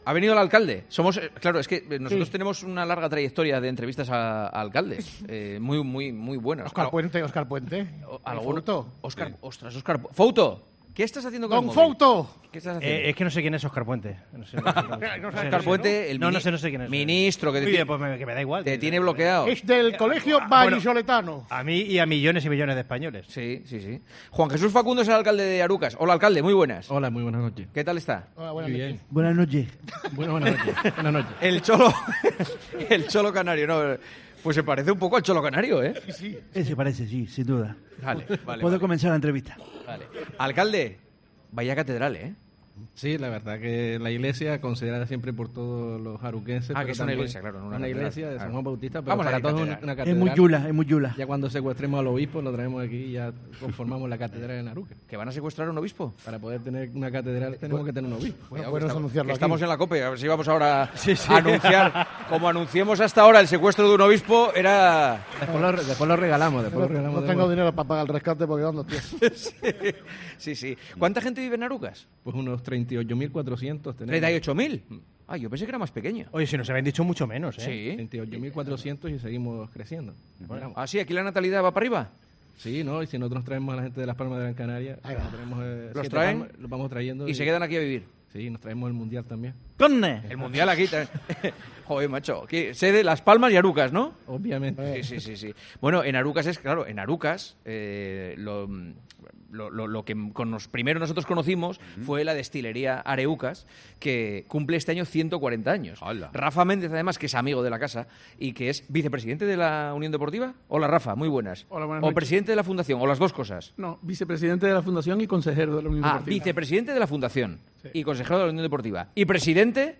desde Gran Canaria.